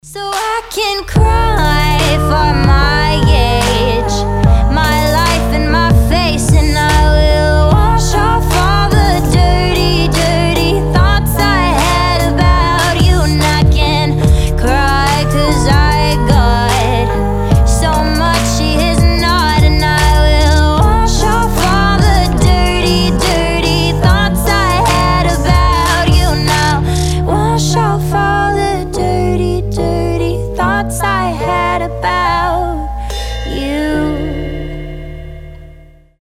• Качество: 320, Stereo
красивый женский голос
indie pop